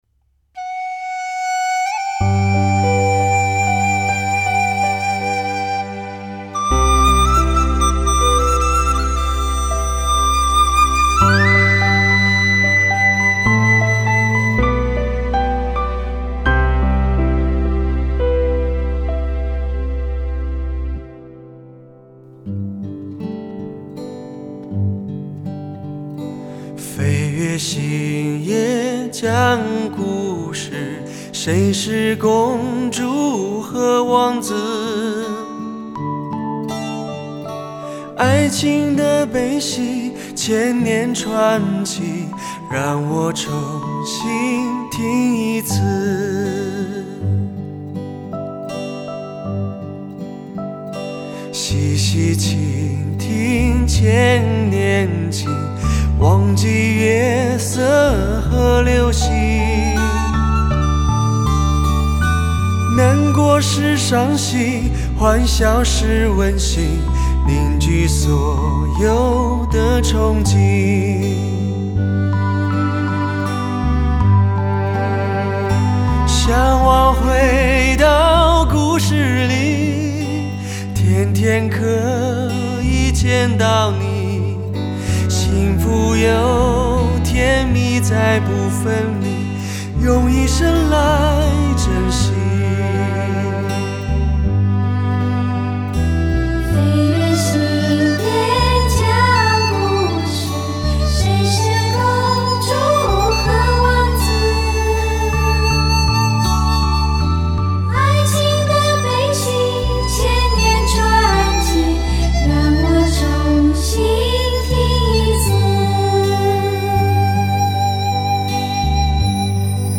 男性美声感性十足,音色质感和定位靓绝,伴奏乐器音色鲜明
人声现场超乎想像, 余韵清晰经久不散!